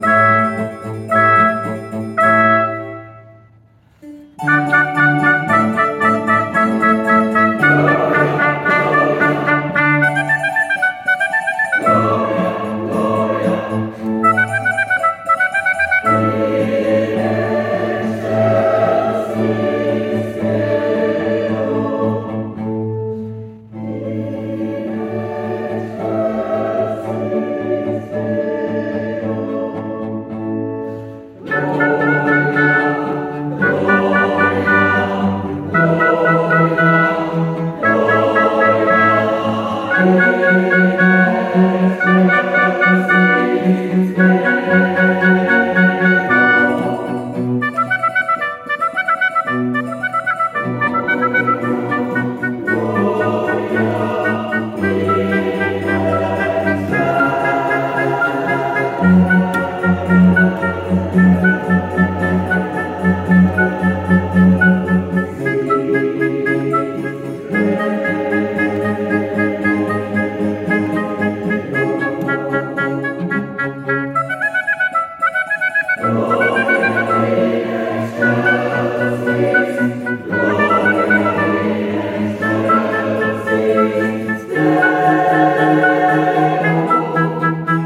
The following audio recordings are snippets from previous concerts to give you a taste of our repertoire